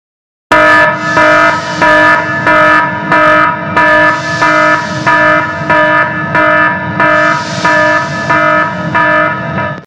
Panic red alert